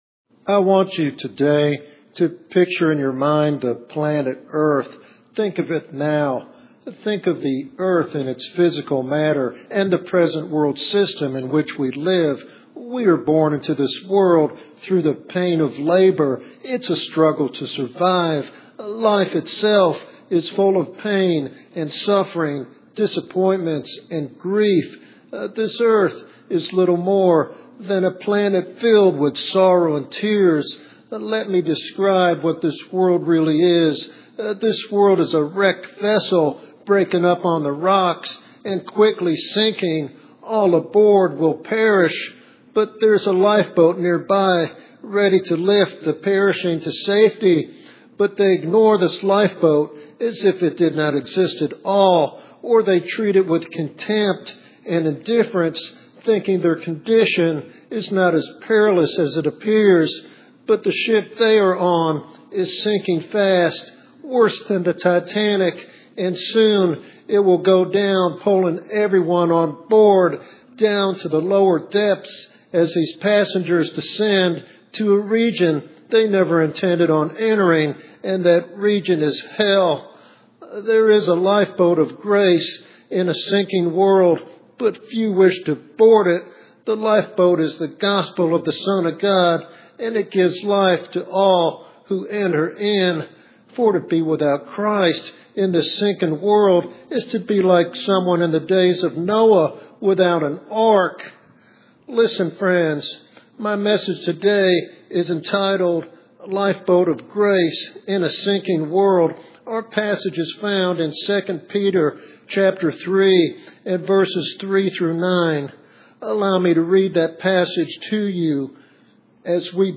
This sermon is a powerful evangelistic appeal rooted in biblical truth and the love of God.